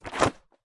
书籍 " 书籍 木地板07
描述：登陆在木地板上的书的几个声音。这些都是一个干净的拍摄，旨在用于视频游戏，或当您需要每个书籍影响有自己的声音。
标签： 地面 木制 地板 木材 冲击
声道立体声